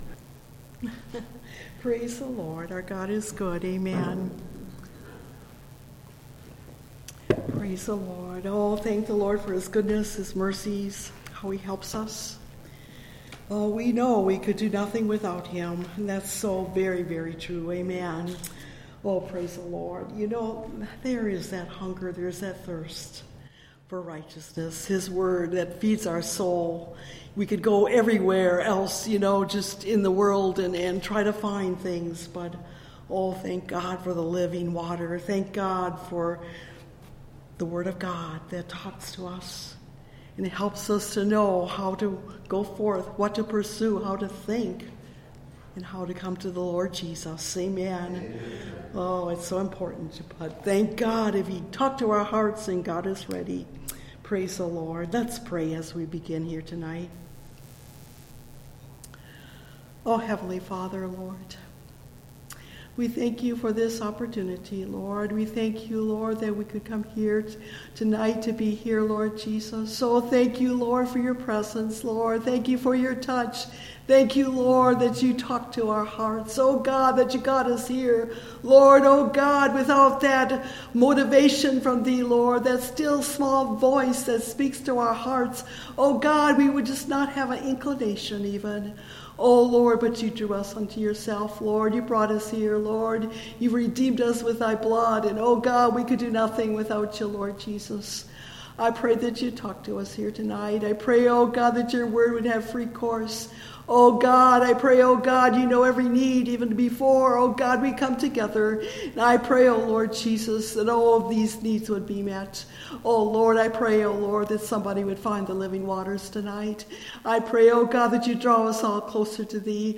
Come Ye To The Waters – Part 2 (Message Audio) – Last Trumpet Ministries – Truth Tabernacle – Sermon Library